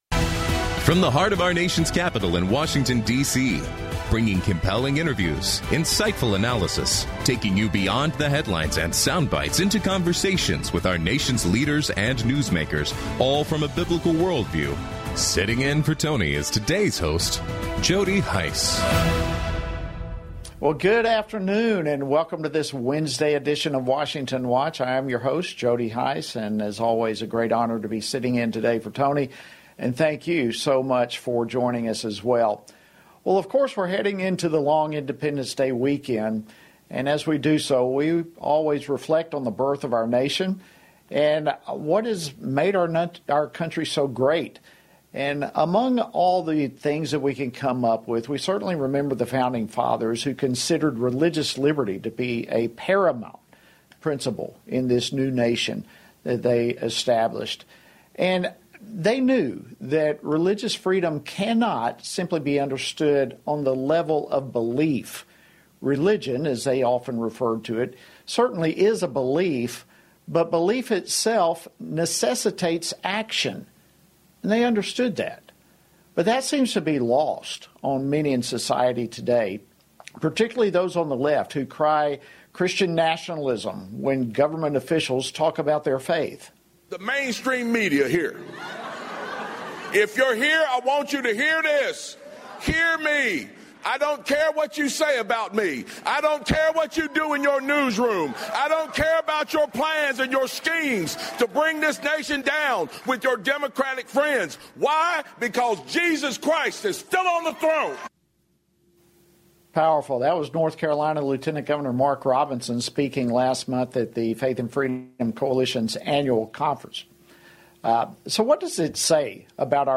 On today’s program, hosted by Jody Hice: Mark Robinson, Lieutenant Governor of North Carolina, responds to criticism of his recent remarks to a Christian audience declaring that Jesus is on His throne.